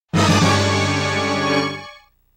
PLAY shock sound effect meme
shock.mp3